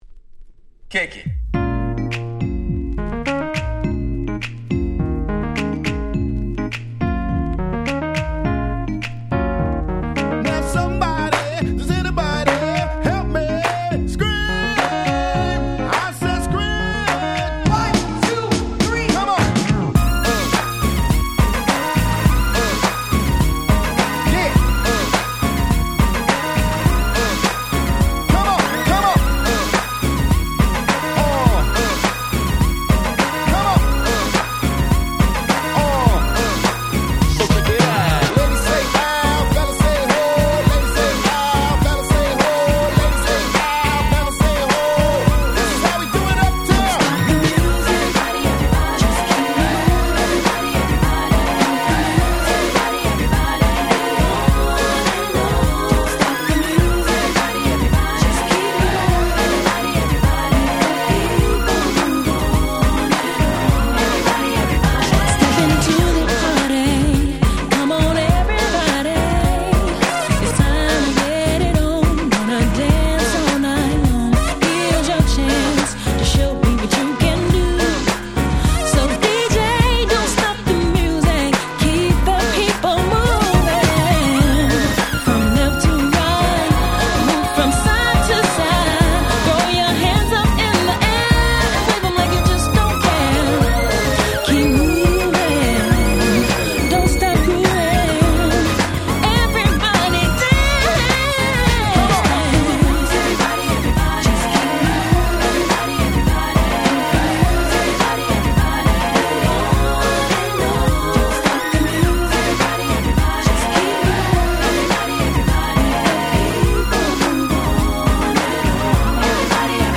95' Super Nice R&B !!